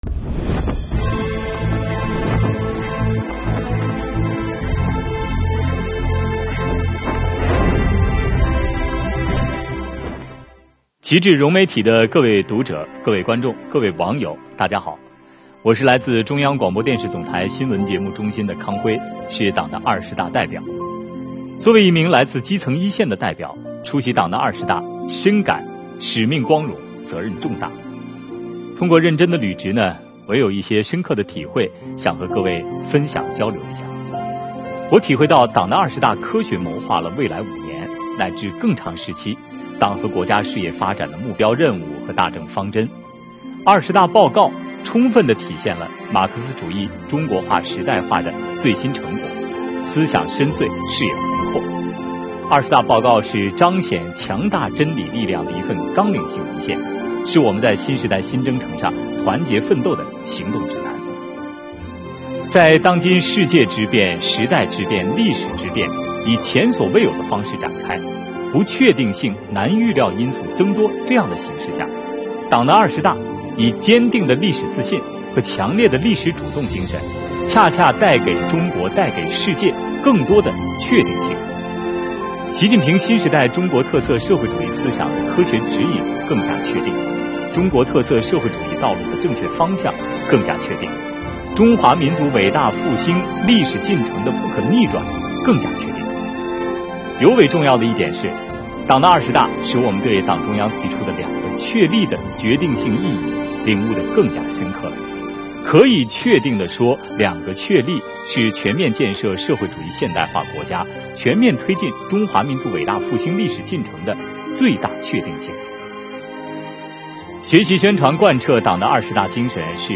本视频为第1期，有请中央广播电视总台新闻节目中心的康辉开讲。